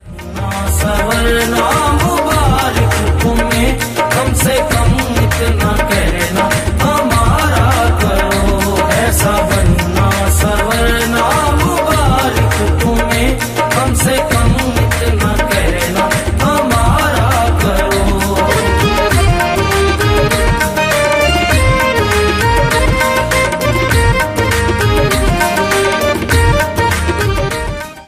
Ringtone File
qawwali style devotional song